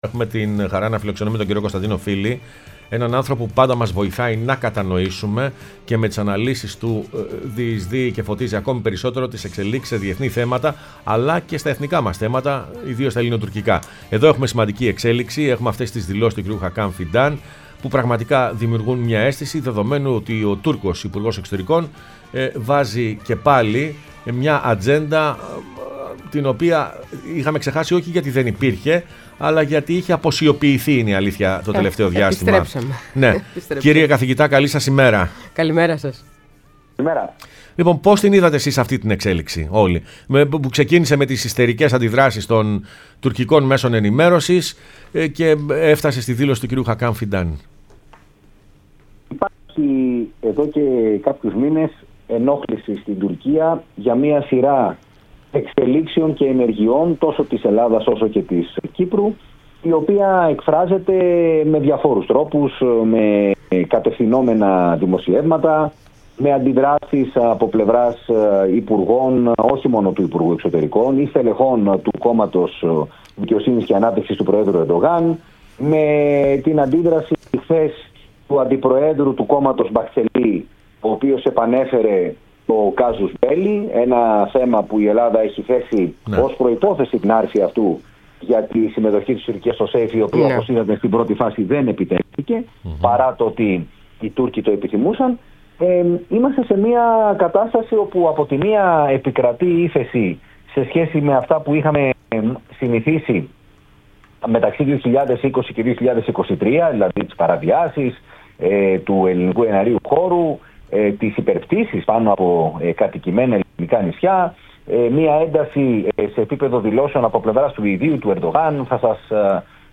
μίλησε στην εκπομπή Πρωινές Διαδρομές